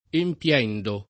empL$ndo], il part. pres. empiente [empL$nte] — da empire o da empiere il pass. rem. (empii [